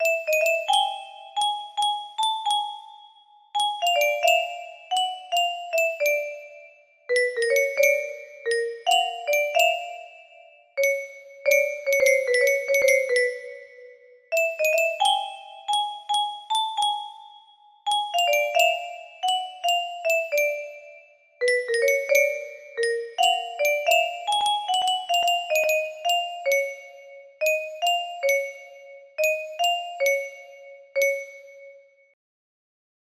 This is a music box version